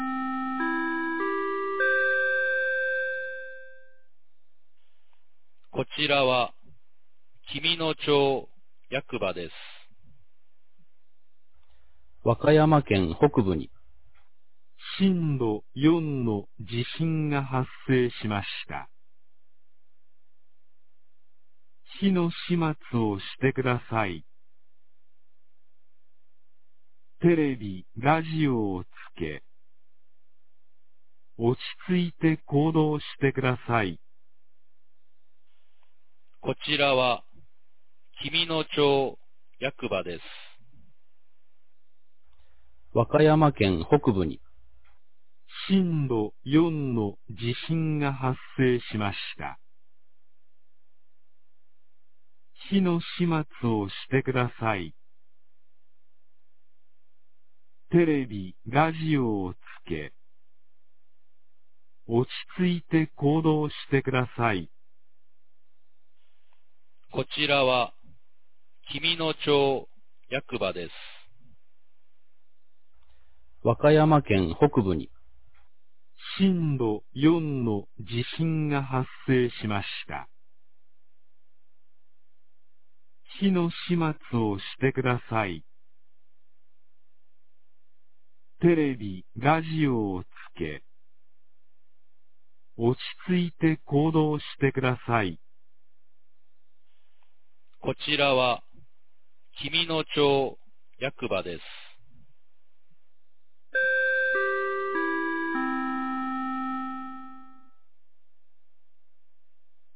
2024年02月07日 21時03分に、紀美野町より全地区へ放送がありました。